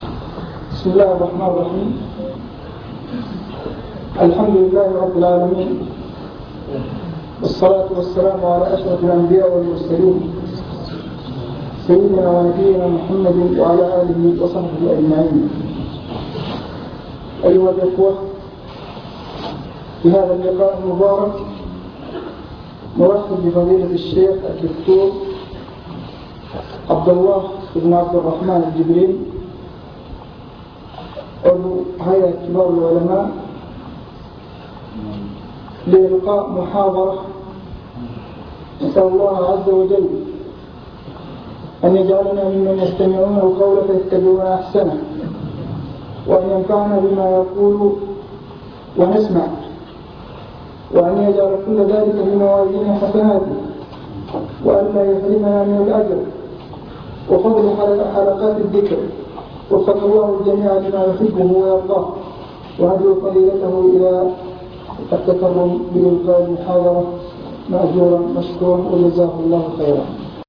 المكتبة الصوتية  تسجيلات - محاضرات ودروس  محاضرة بعنوان شكر النعم (3)
تقديم